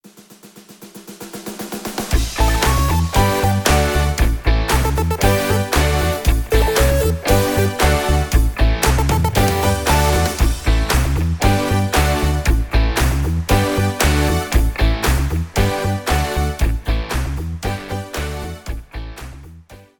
3 Part Mix